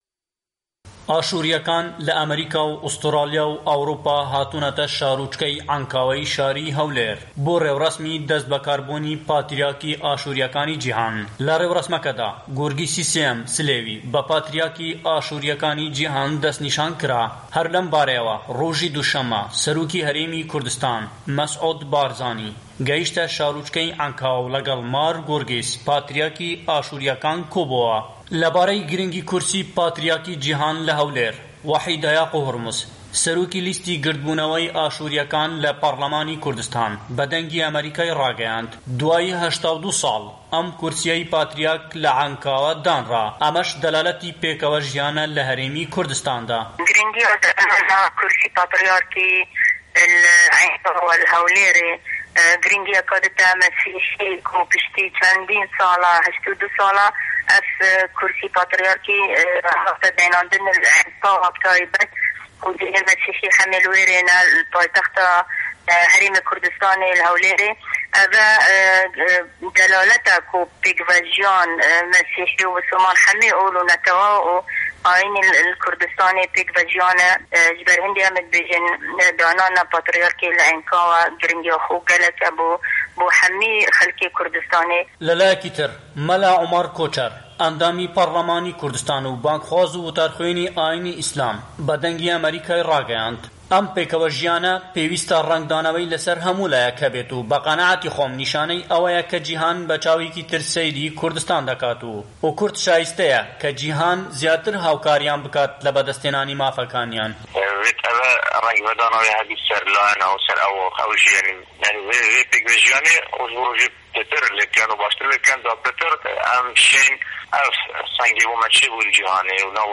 ڕاپـۆرتێـک سەبارەت بە دەستبەکاربوونی پاتریارکی ئاشوریـیەکانی جیهان لە عەینکاوە